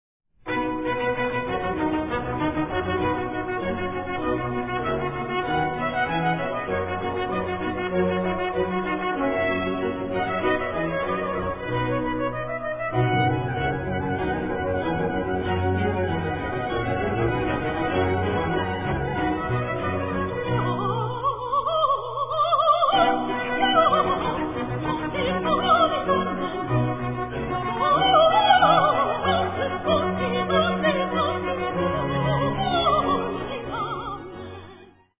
Three virtuosic soprano solo cantatas
Performed on period instruments.
1. Aria: